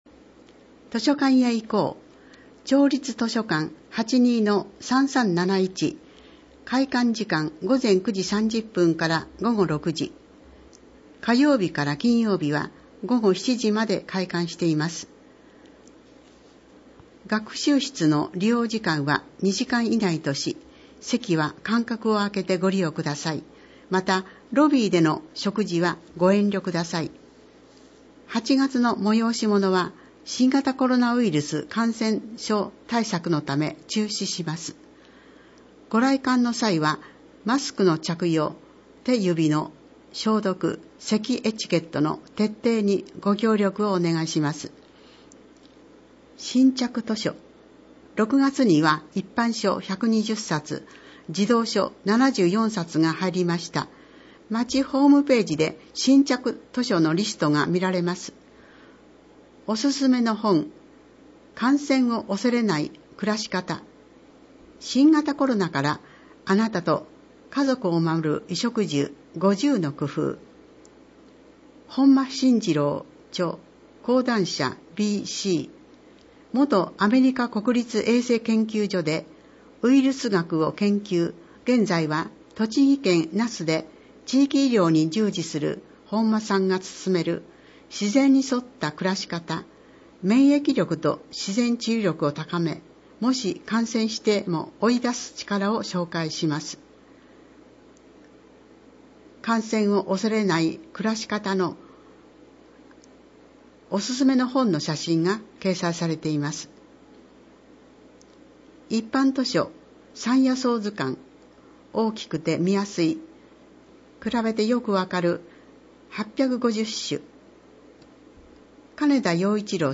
また、音訳ボランティアサークルおとわの会のみなさんによる広報の音訳版のダウンロードもご利用ください。
（PDF文書）   広報音訳版ダウンロード（制作：おとわの会）